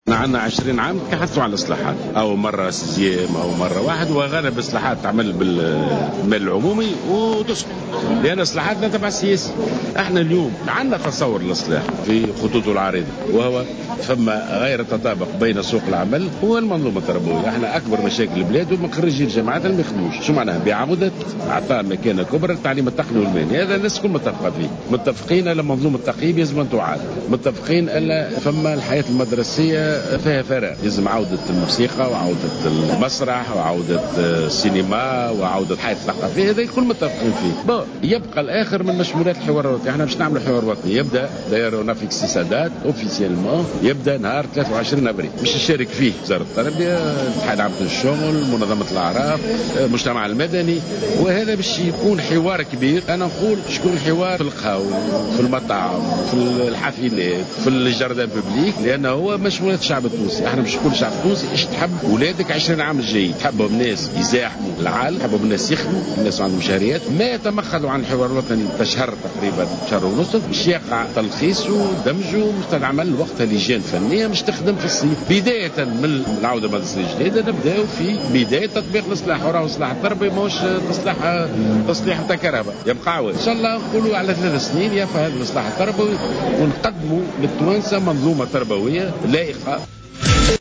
تحدّث وزيرُ التربية ناجي جلول خلال حُضوره اليوْم بمجلس نوّاب الشعب، لمراسل الجوهرة أف أم عن مشروع الإصلاح التربوي والحوار الوطني المُقرّر في 23 أفريل الحالي.